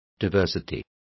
Complete with pronunciation of the translation of diversity.